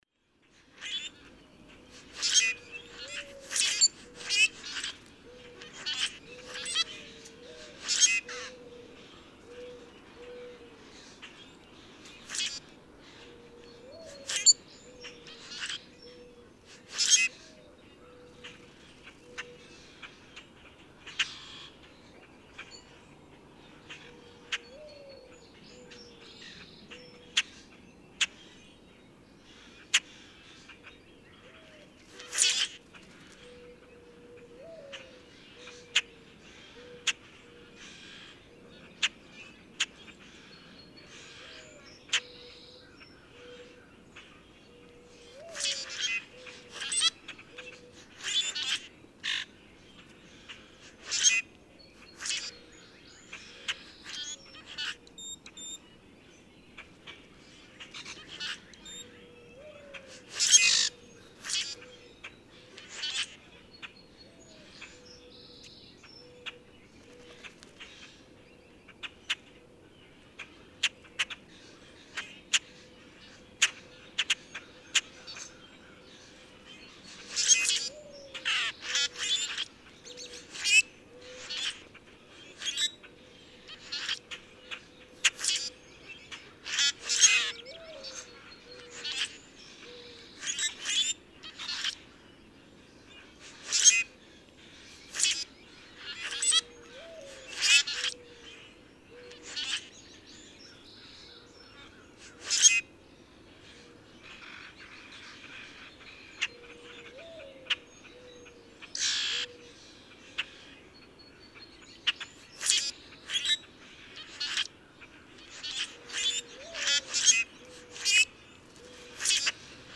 Common grackle
The female sings most often early in the spring, usually when "answering" her mate, with the two songs coming in rapid succession.
Hatfield, Massachusetts.
Here's the longer recording from which those few songs of the breeding colony in ♫692 were extracted. Perhaps you can find more examples of songs from those three males, or listen for other individuals, but for the most part just enjoy the great variety of songs among the birds in this flock.
693_Common_Grackle.mp3